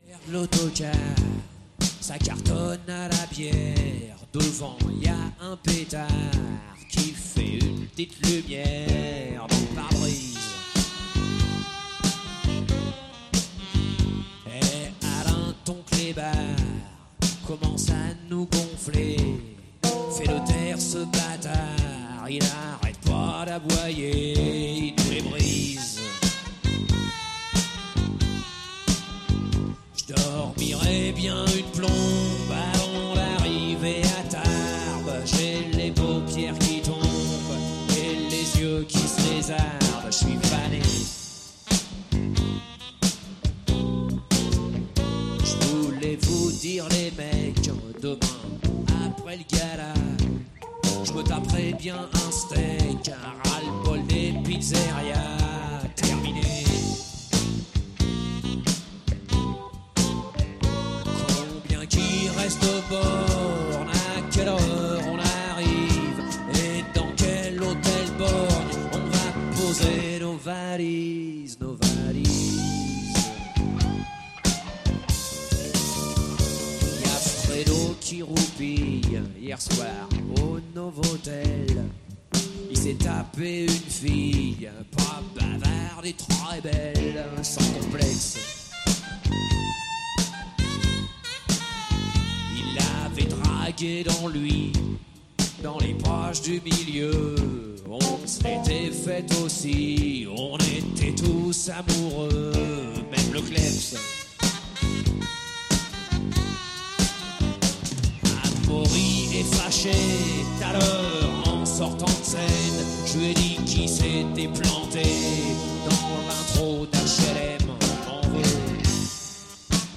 chanson française mais aussi, du blues, du rock, du reggae, du rap, jazz, etc.